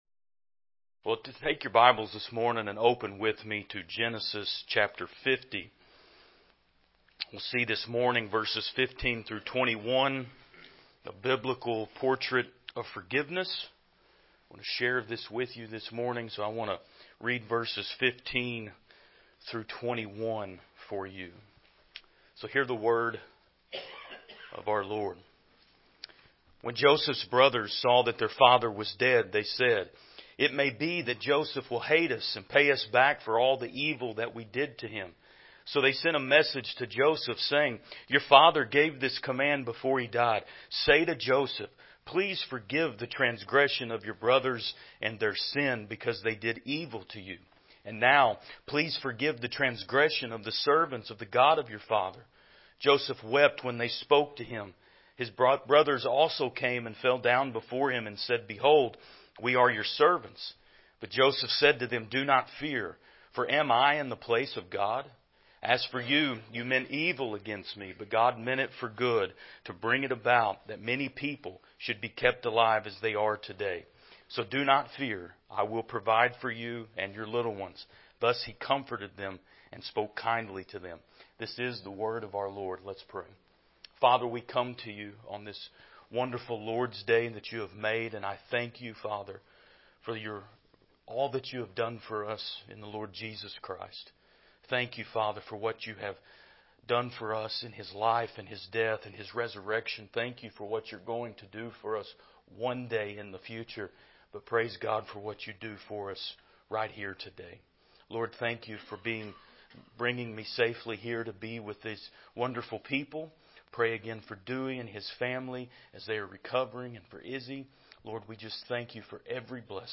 Passage: Genesis 50:15-21 Service Type: Sunday School « The Gospel Is Motivation For God-Honoring Church Life